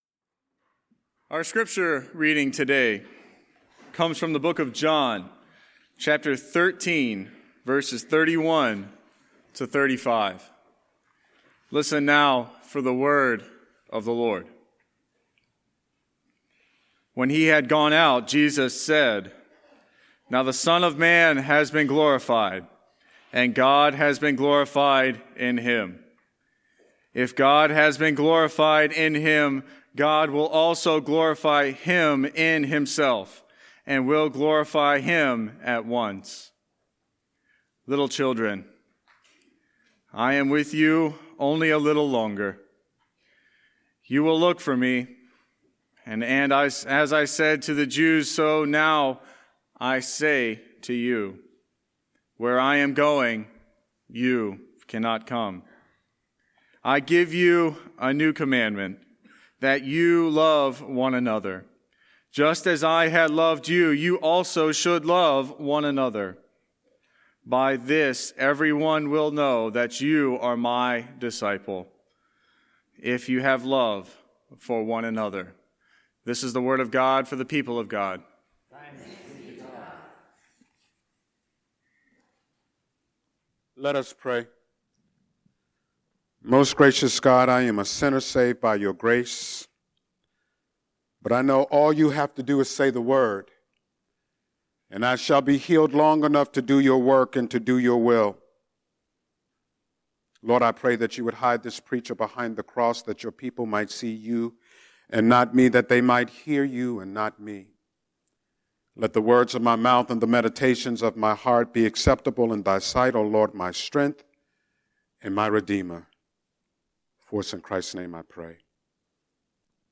04-24-Scripture-and-Sermon.mp3